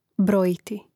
bròjiti brojiti